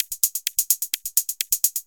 Index of /VEE/VEE Electro Loops 128 BPM
VEE Electro Loop 096.wav